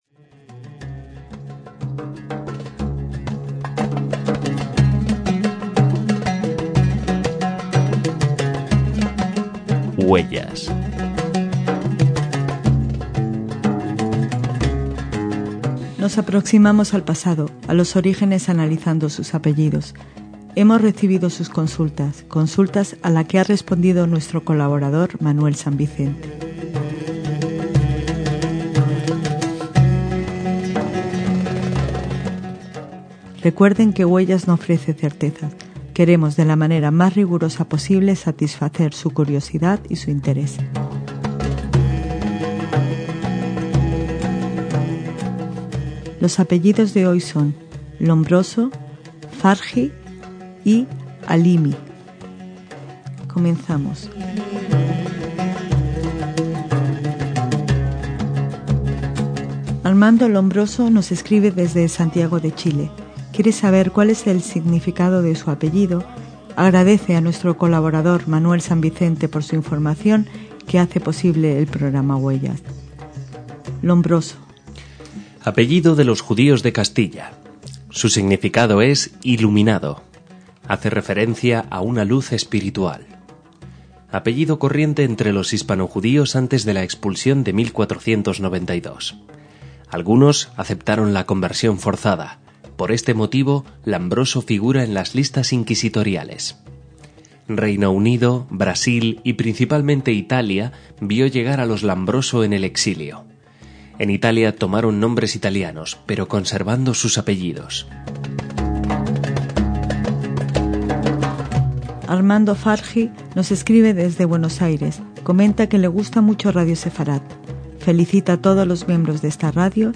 FARHI (se pronuncia Farji)